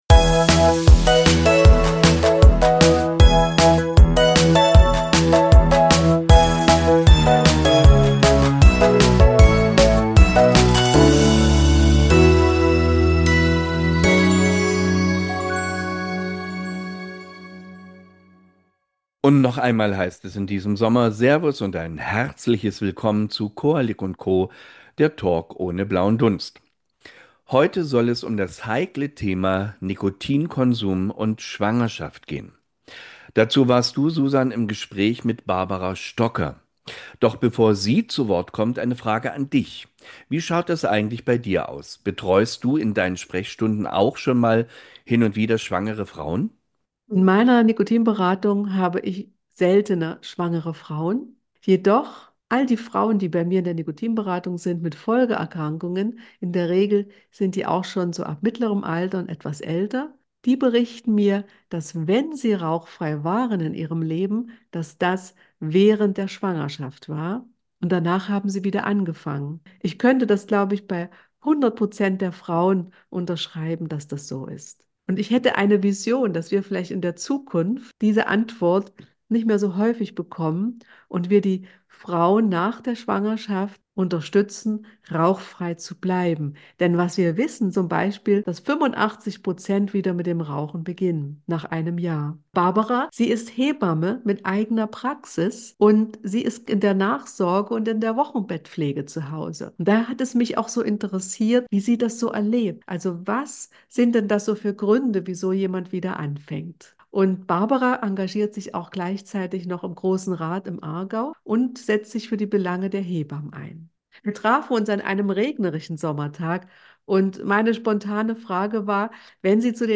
Beschreibung vor 7 Monaten In dieser Folge spreche ich mit Barbara Stocker, Hebamme mit eigener Praxis und Mitglied des Grossen Rates im Kanton Aargau, über ihren Berufsalltag in der Wochenbettbetreuung. Mir war wichtig zu erfahren, wie ihr das Thema Rauchen in Familien begegnet – nicht aus der Rolle einer Expertin, sondern aus ihrer praktischen Erfahrung im Alltag.